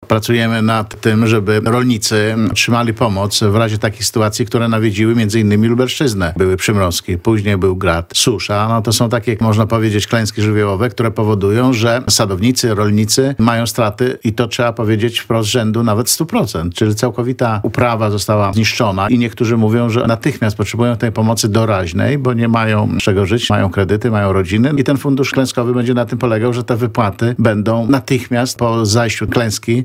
– Fundusz Klęskowy ma pomóc rolnikom dotkniętym skutkami przymrozków, gradu czy suszy – powiedział w porannej rozmowie w Radiu Lublin wiceminister rolnictwa i rozwoju wsi Jacek Czerniak.